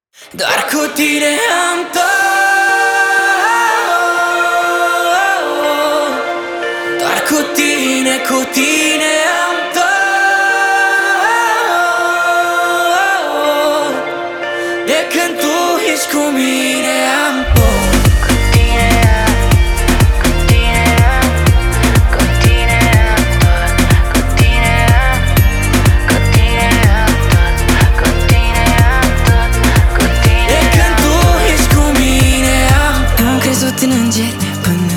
2025-06-09 Жанр: Поп музыка Длительность